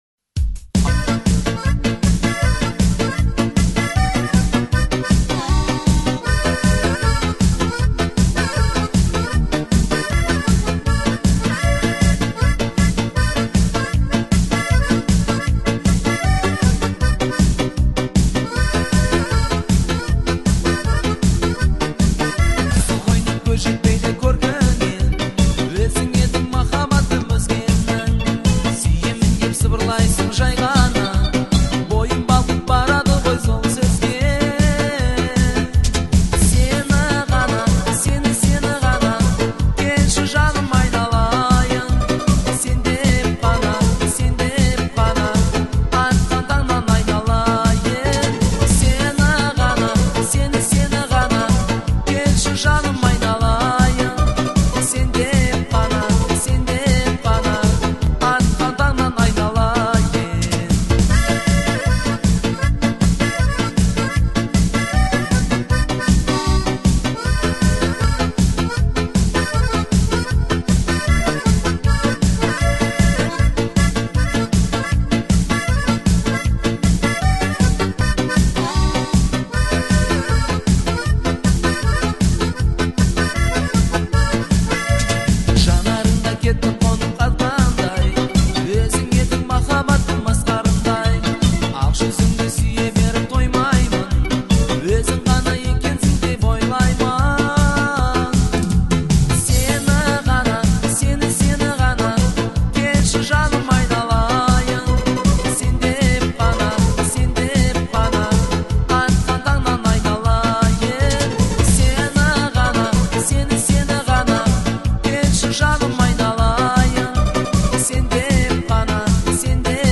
это трогательная песня в жанре поп